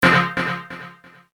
radar.mp3